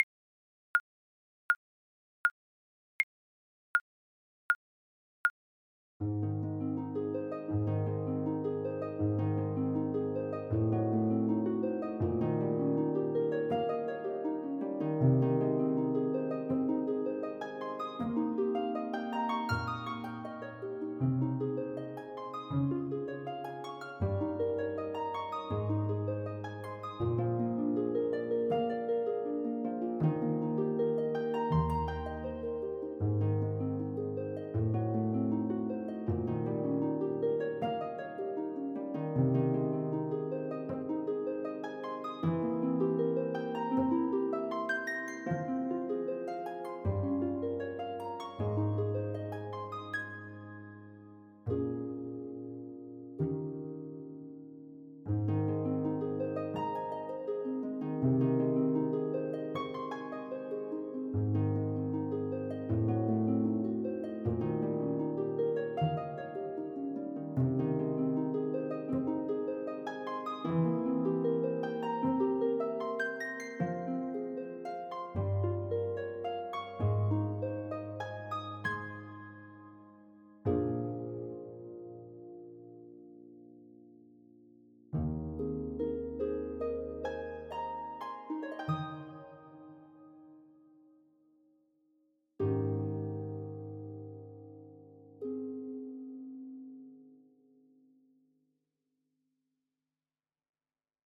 Clarinet  (View more Easy Clarinet Music)
Classical (View more Classical Clarinet Music)